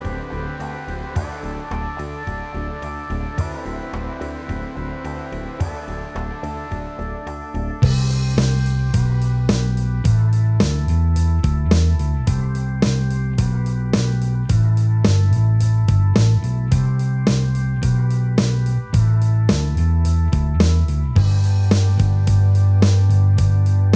Minus Guitars Indie / Alternative 5:08 Buy £1.50